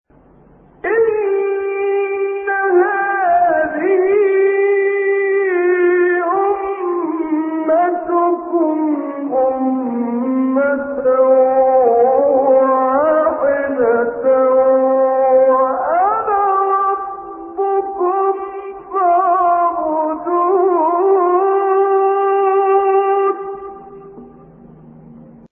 6 فراز صوتی در مقام «کُرد»
گروه شبکه اجتماعی: فرازهایی صوتی از تلاوت شش قاری برجسته مصری که در مقام کُرد اجرا شده‌اند، ارائه می‌شود.